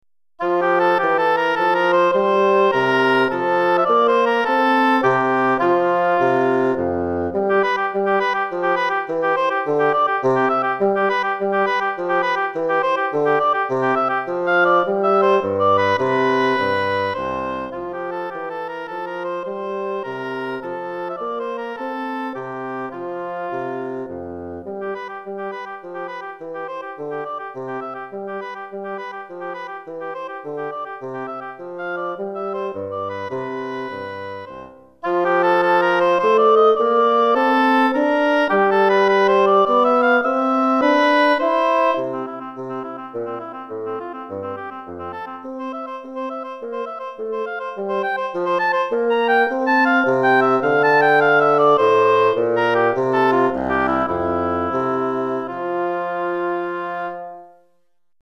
Hautbois et Basson